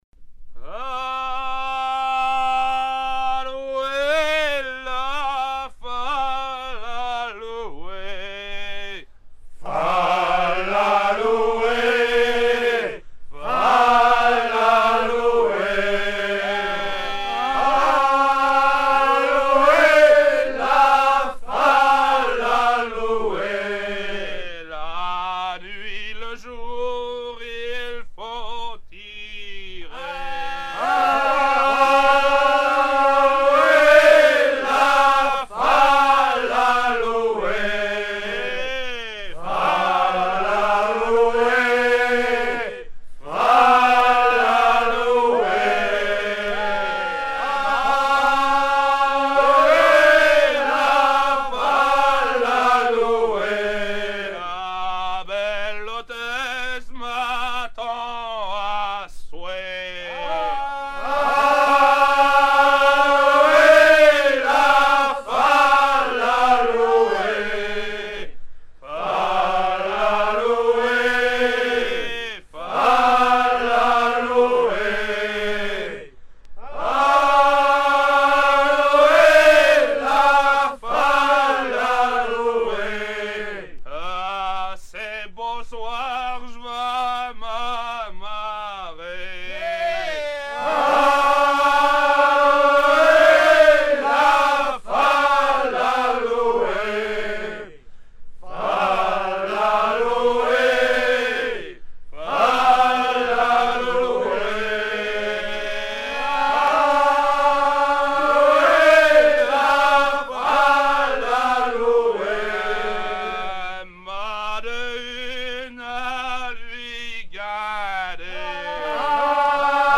chants brefs
(les paroles sont improvisées)
Chants de marins traditionnels des côtes de France